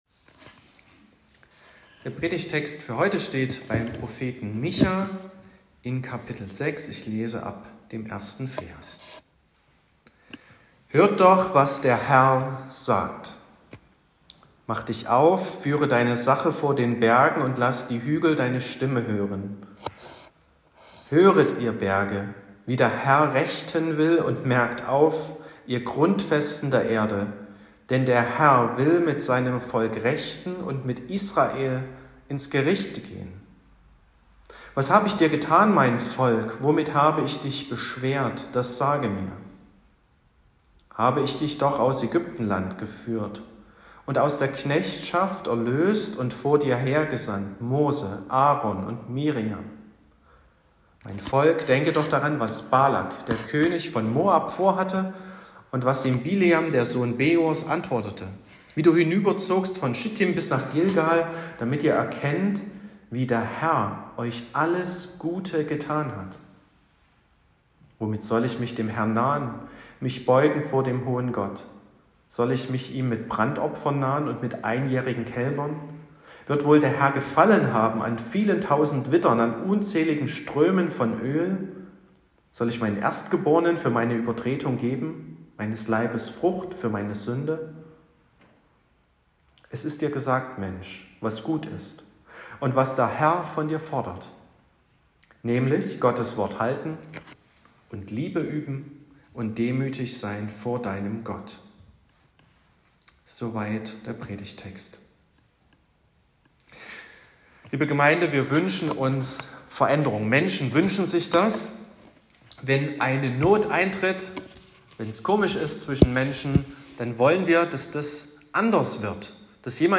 27.10.2024 – Gottesdienst
Predigt (Audio): 2024-10-27__Wir_muessen_reden___-_Gott.m4a (7,7 MB)